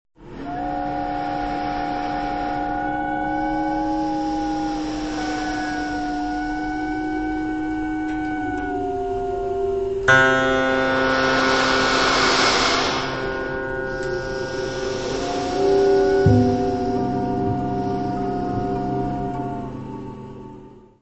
barrel organ
piano, prepared piano
tuba, serpent
double bass
percussion
Music Category/Genre:  New Musical Tendencies